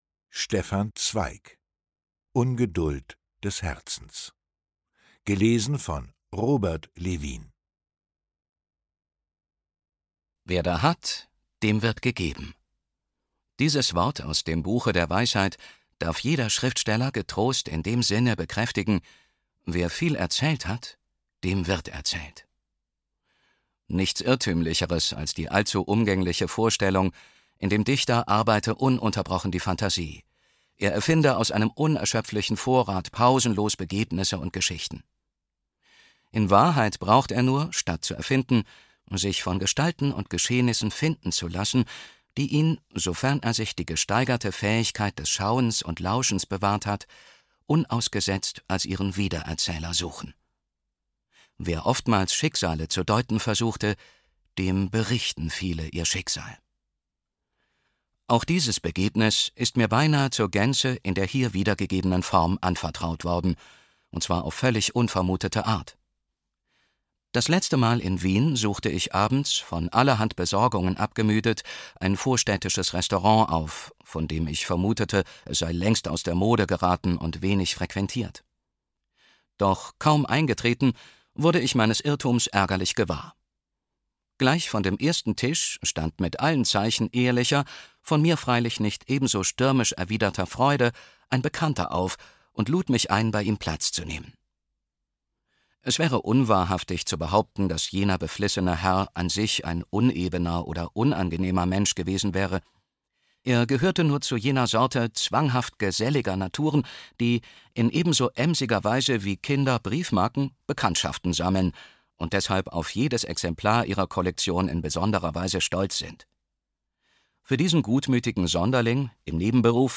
»Hier wird fündig, wer an Hörbuchproduktionen Freude hat, die nicht schnell hingeschludert sind, sondern mit einer Regie-Idee zum Text vom und für den Rundfunk produziert sind.« NDR KULTUR
»Die Hörbuch-Edition ›Große Werke. Große Stimmen.‹ umfasst herausragende Lesungen deutschsprachiger Sprecherinnen und Sprecher, die in den Archiven der Rundfunkanstalten schlummern.« SAARLÄNDISCHER RUNDFUNK